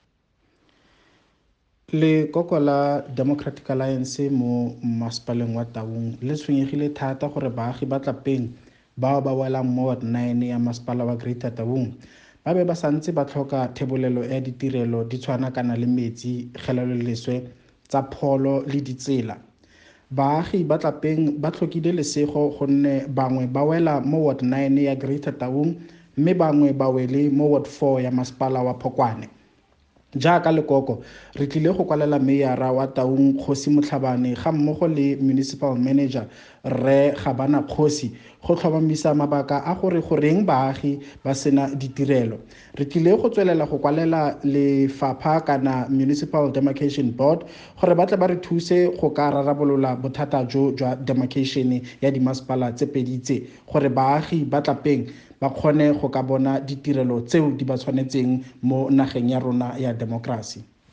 Setswana by Sello Seitlholo, MP, the DA Constituency Head in Greater Taung Local Municipality.
Sello-Seitlholo-Setswana.mp3